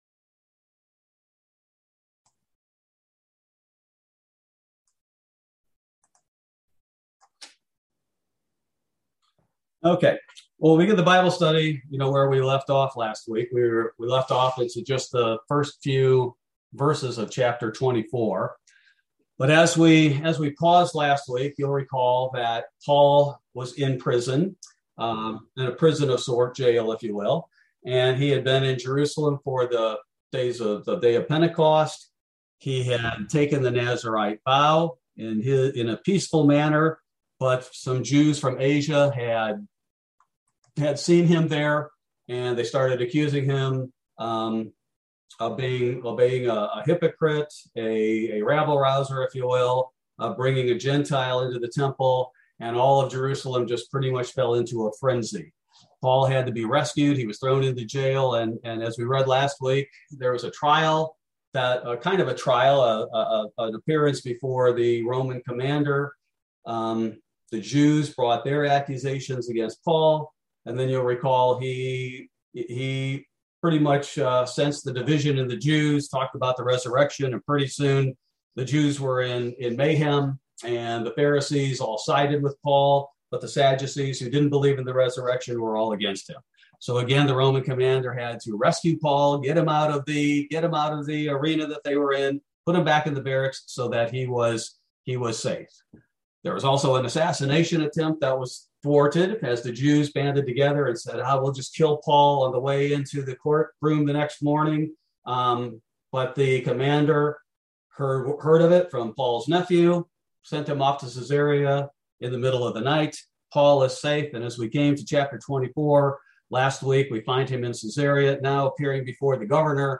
Bible Study: January 12, 2022